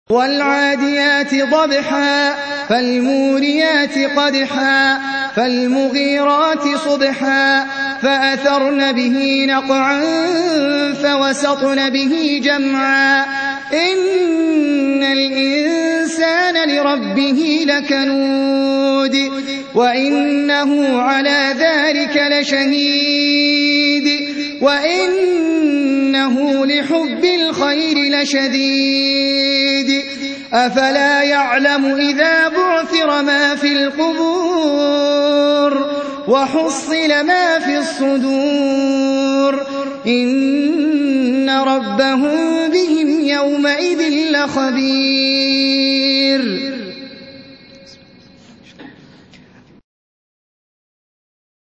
Surah Sequence تتابع السورة Download Surah حمّل السورة Reciting Murattalah Audio for 100. Surah Al-'Adiy�t سورة العاديات N.B *Surah Includes Al-Basmalah Reciters Sequents تتابع التلاوات Reciters Repeats تكرار التلاوات